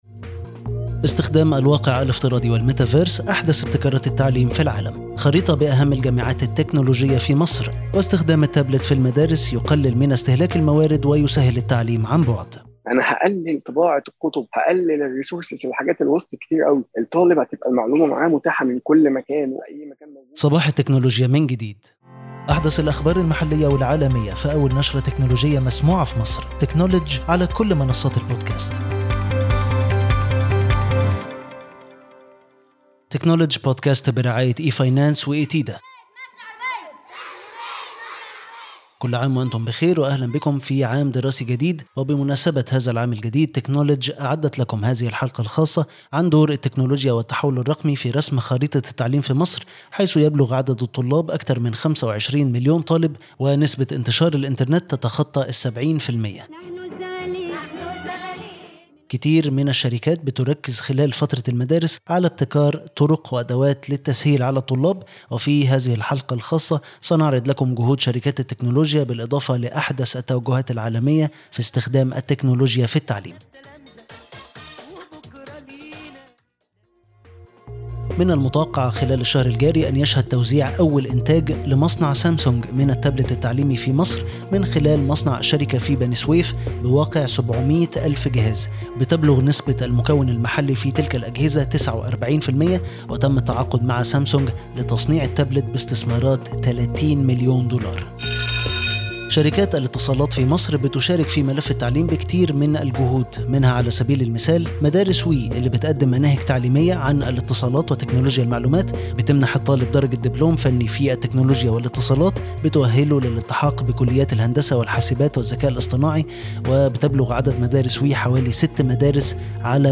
مرحبًا بكم في تكنولدج بودكاست أول نشرة تكنولوجية مسموعة فى مصر تأتيكم بأحدث الأخبار المحلية والعالمية، وتقربكم من دائرة صناعة القرار